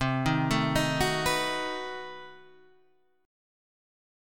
B7/C Chord